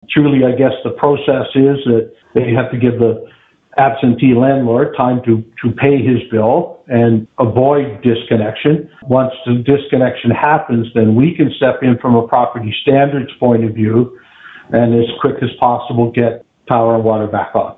myFM spoke with Mayor Joe Preston Monday afternoon about the threat to tenants.